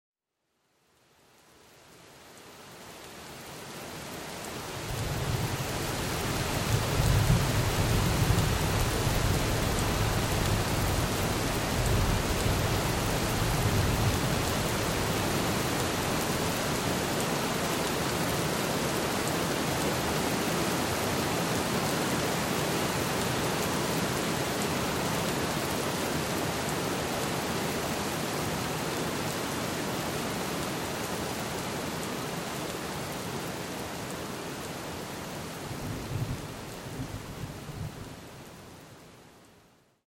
Nachtgewitter mit Donner (MP3)
Bei Geräuschaufnahmen sind diese ebenfalls in 44.1 kHz Stereo aufgenommen, allerdings etwas leister auf -23 LUFS gemastert.
44.1 kHz / Stereo Sound
MP3-Hoerprobe-Nachtgewitter.mp3